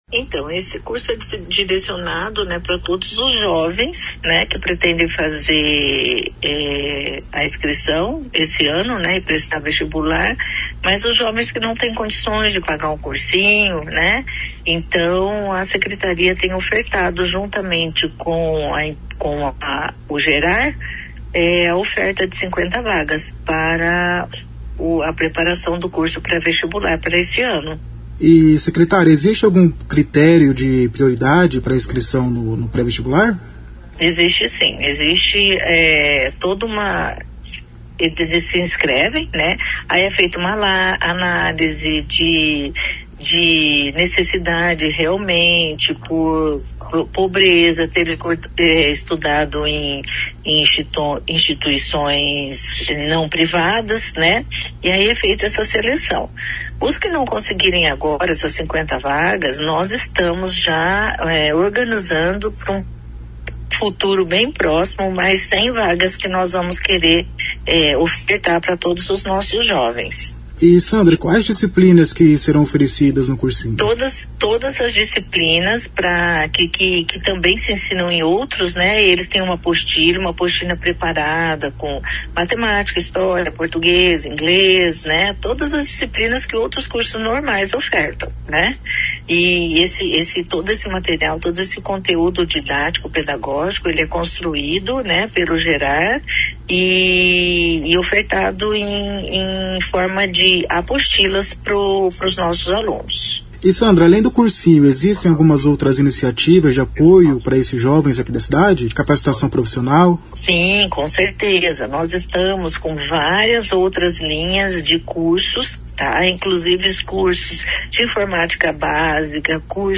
A secretária de Juventude, Cidadania e Migrante, Sandra Franchini, diz que os estudantes selecionados terão ajuda de custo e orientação vocacional.